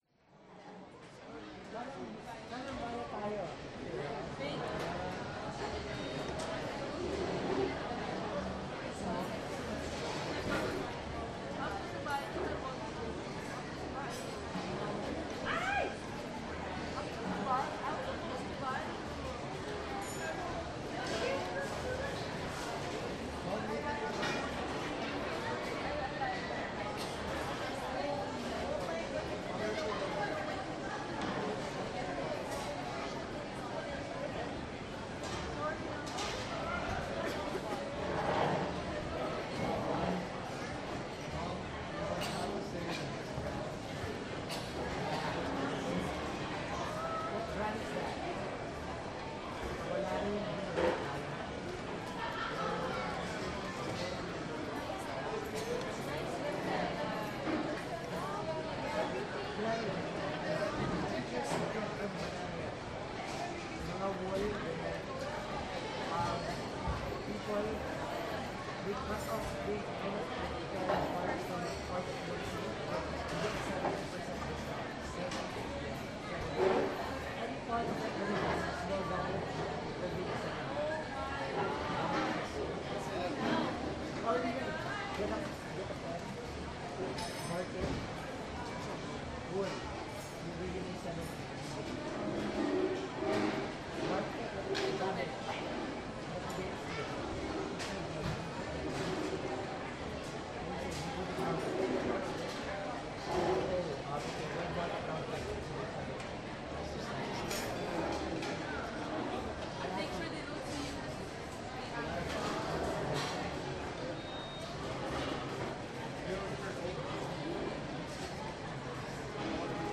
Food Court
After hours of shopping at the San Francisco Westfield Mall, we had pasta and a gigantic salad from the gourmet food court. The decor is pretty retro hip, but the tile floors emphasize every little sound, from people chatting to chairs sliding to silverware clinking to heels clomping… creating an interesting mélange.
westfield-food-court.mp3